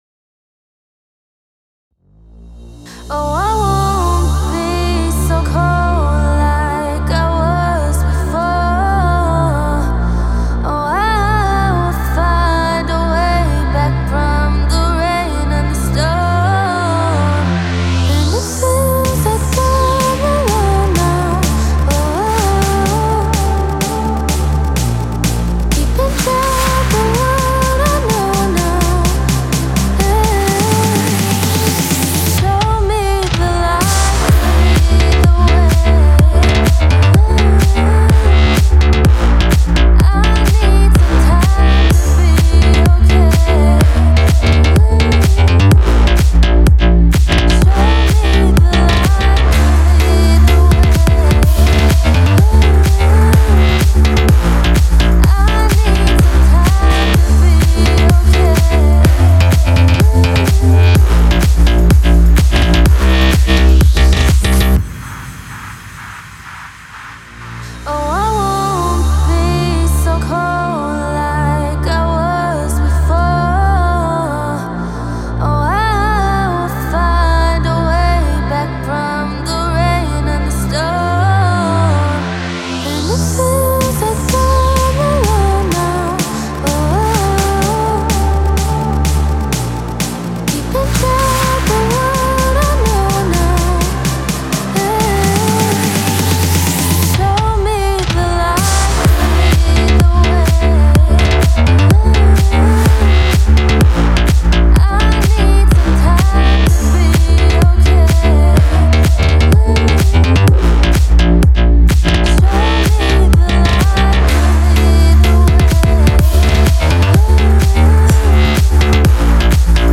эмоциональная поп-песня